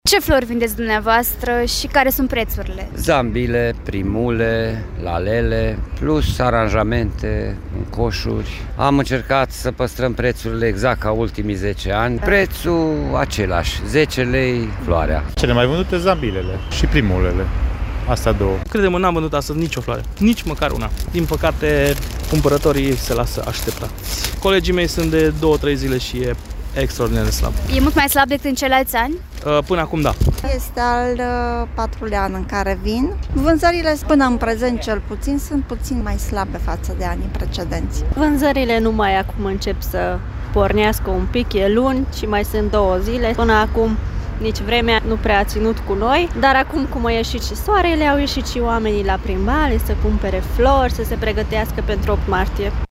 Anul acesta însă, târgumureșenii se arată mai puțin interesați să achiziționeze astfel de produse, se plâng expozanții: